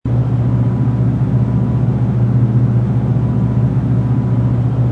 ambience_deck_space_smaller.wav